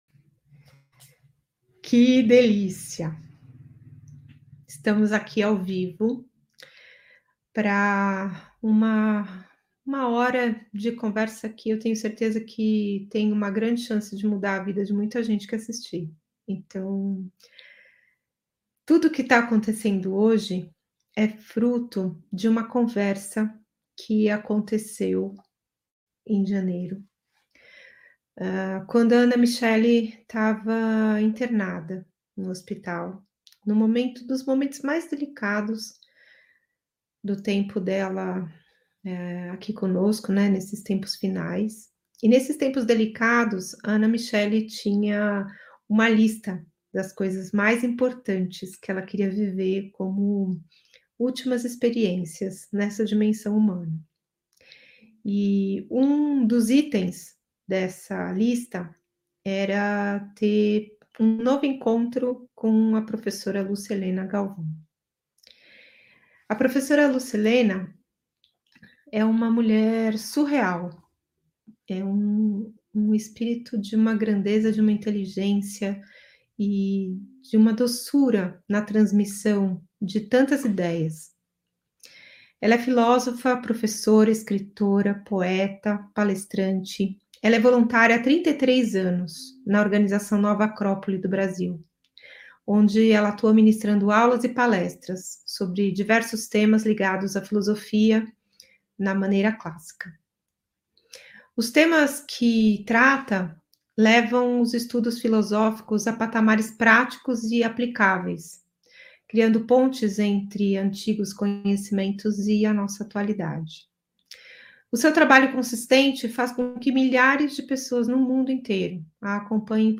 Transmitido ao vivo em 26 de abr. de 2023